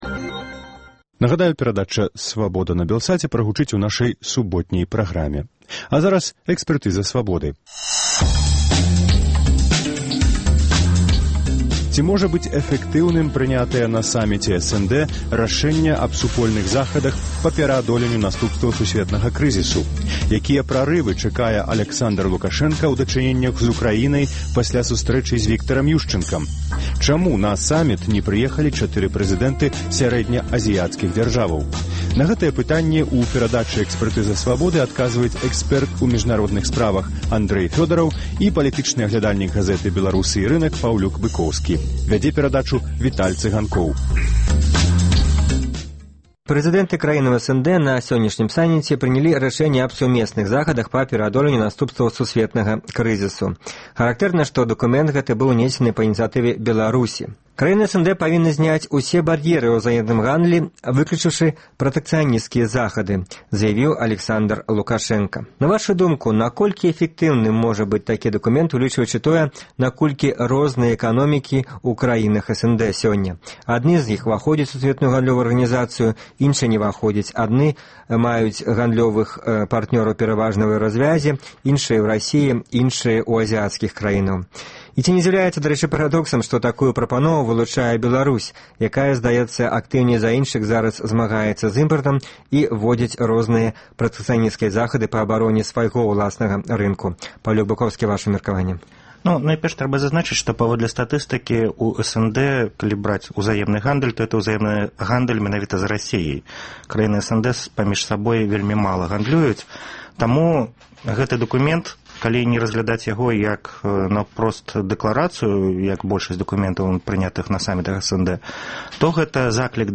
Ці можа быць эфэктыўным прынятая на саміце СНД рашэньне аб сумесных захадах па пераадоленьню наступстваў сусьветнага крызісу? Якія прарывы чакае Аляксандар Лукашэнка ў дачыненьнях у Украінай пасьля сустрэчы з Віктарам Юшчанкам? Чаму на саміт не прыехалі 4 прэзыдэнты сярэднеазіяцкіх дзяржаваў? Гэтыя пытаньні абмяркоўваюць экспэрт у міжнародных справаў